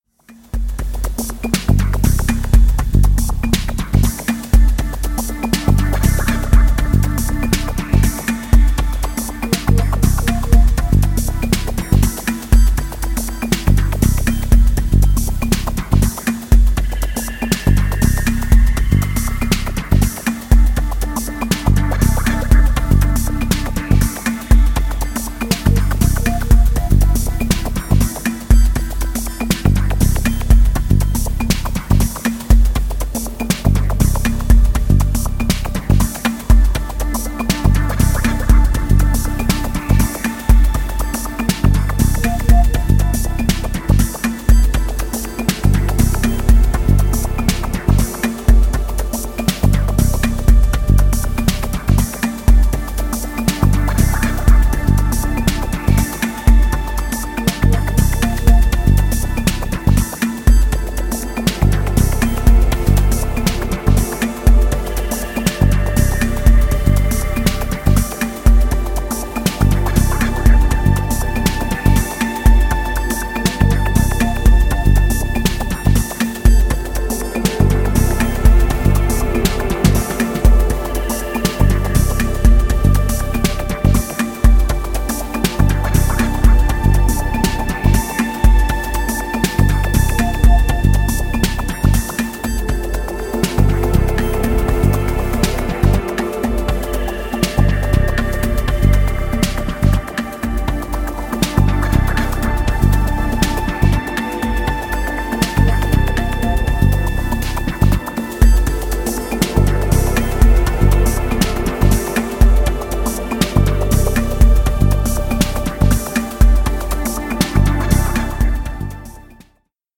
Electro House Techno